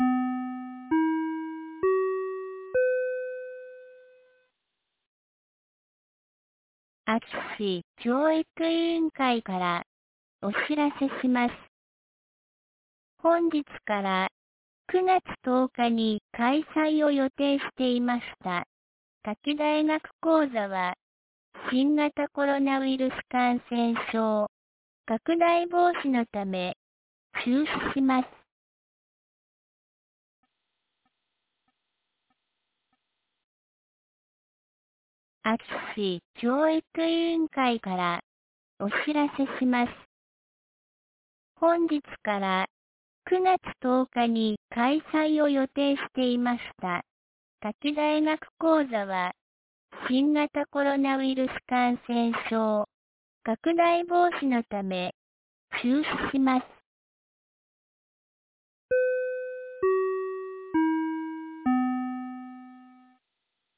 2022年09月04日 12時11分に、安芸市より全地区へ放送がありました。